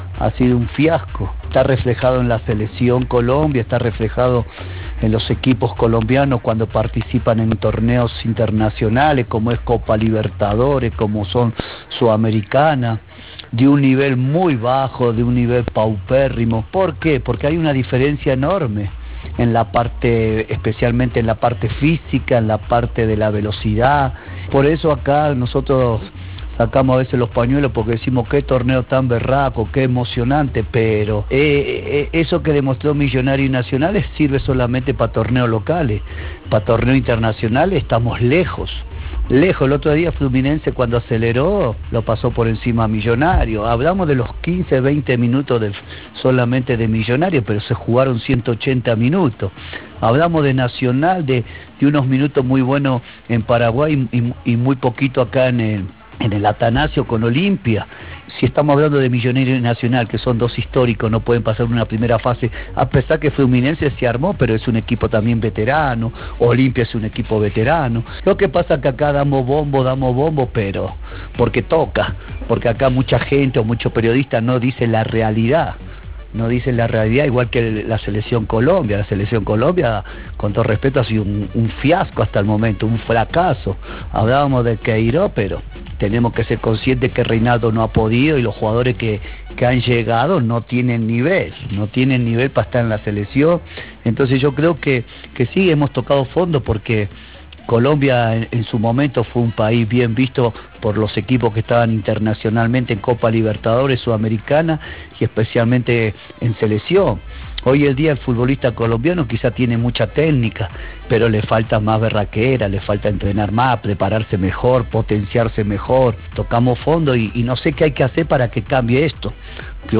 Durante la emisión del programa Blog Deportivo de Bluradio, Mario Vanemerak habló del presente del fútbol colombiano que vive tiempos díficiles.
Entrevista-Mario-Vanemerak.wav